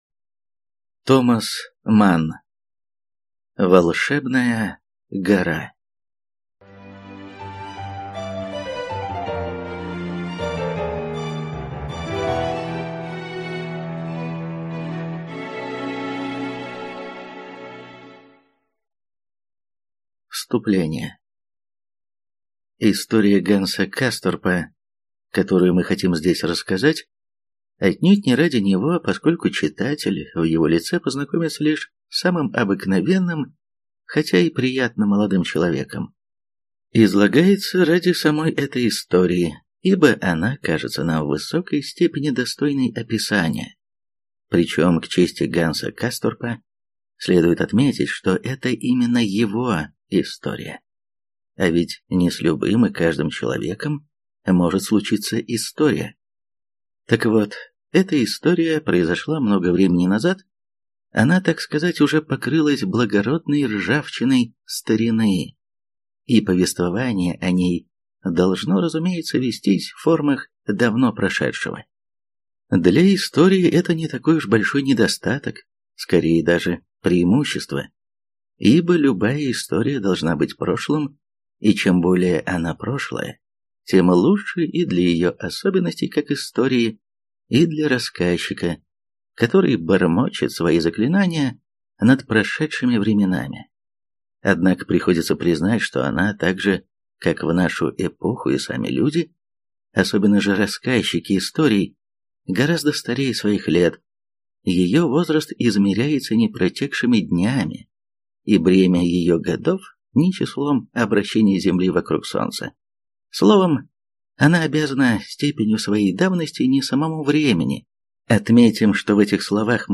Аудиокнига Волшебная гора - купить, скачать и слушать онлайн | КнигоПоиск